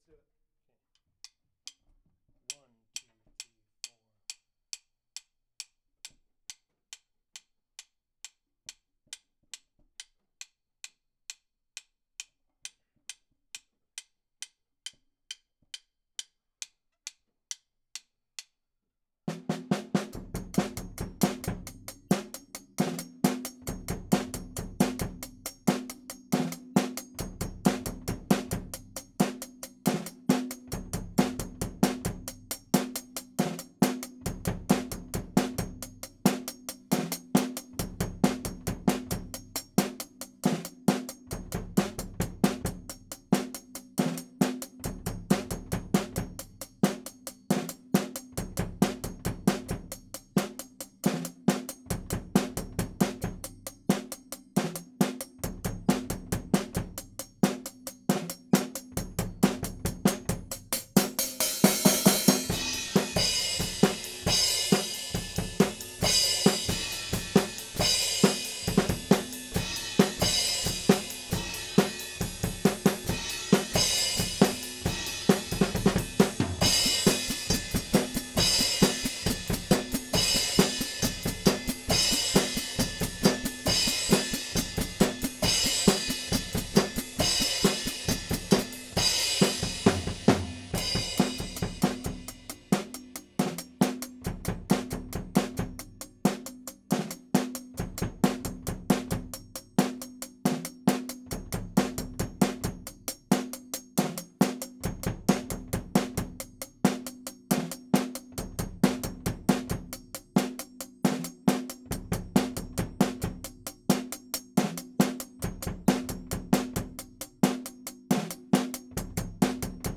Hi Hat (2).wav